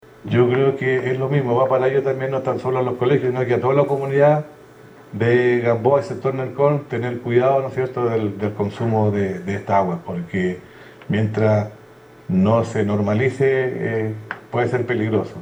En la misma línea, el alcalde Baltazar Elgueta hizo un llamado a los vecinos de los sectores afectados a extremar las precauciones.